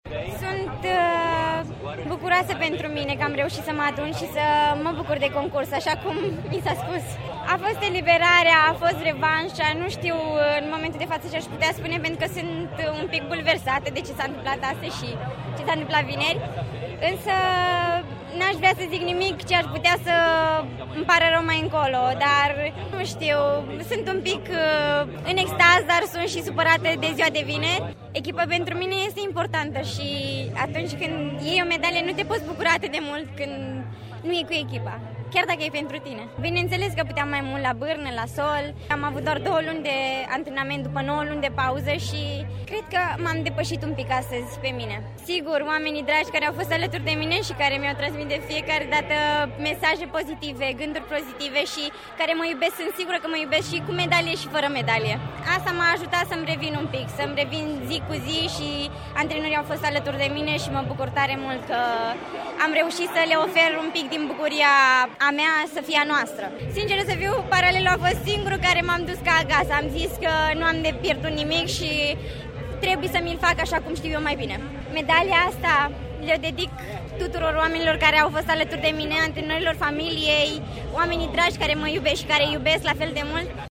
Ascultaţi-o mai jos pe proaspăta medaliată cu bronz la Mondialele din Glasgow: